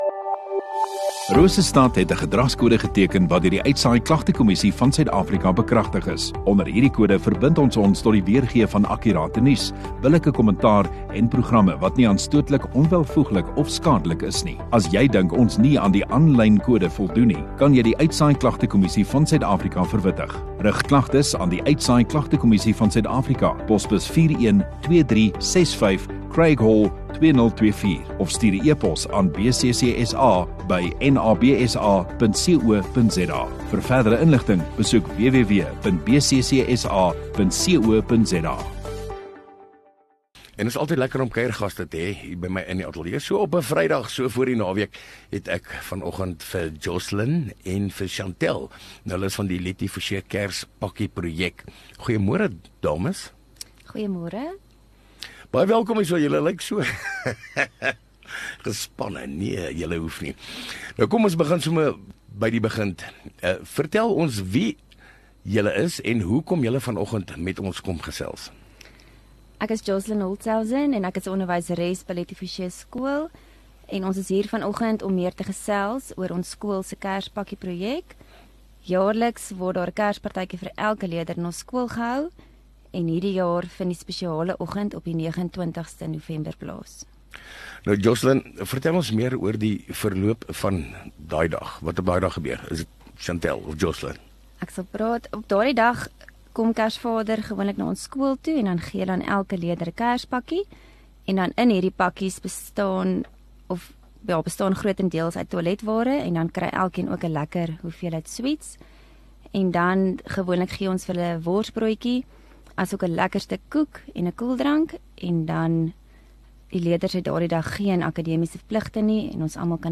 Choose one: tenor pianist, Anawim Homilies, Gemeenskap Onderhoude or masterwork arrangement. Gemeenskap Onderhoude